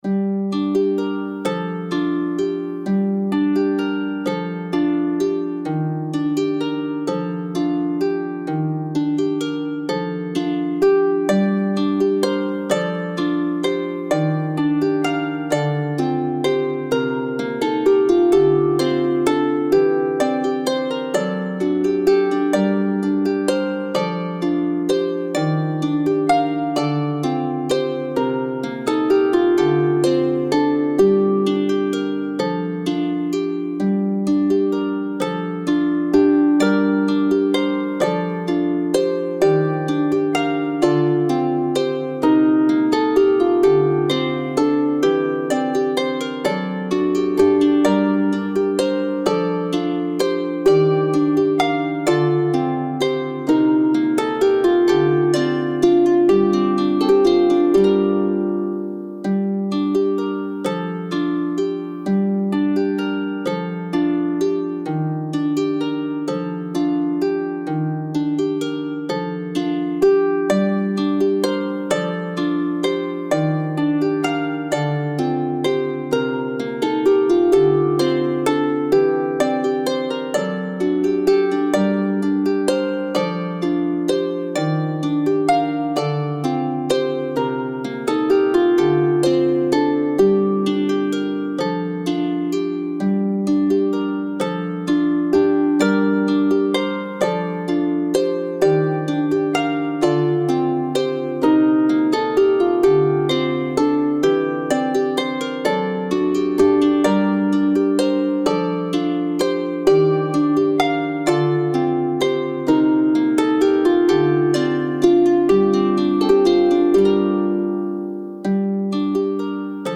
お伽話の最初に流れる吟遊詩人の語りのようなハープ曲です タグから似たような曲をさがす